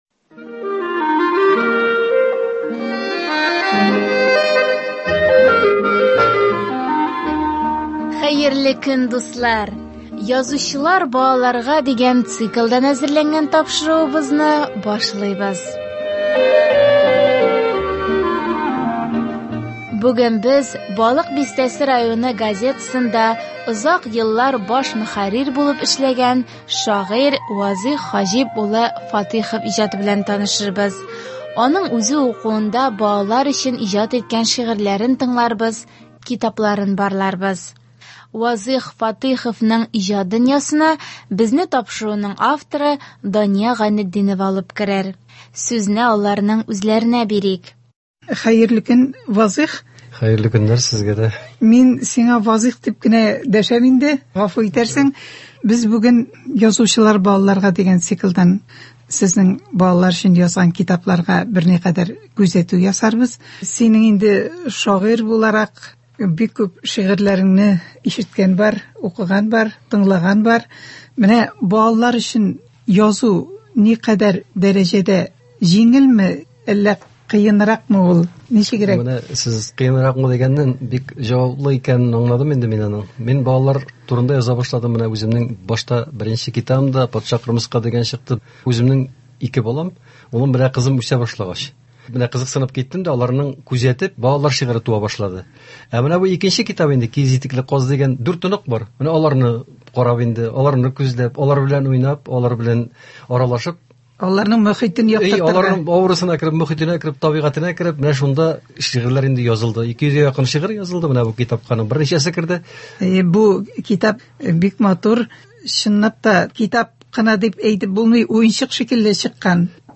аның үзе укуында балалар өчен иҗат иткән шигырьләрен тыңларбыз, китапларын барларбыз.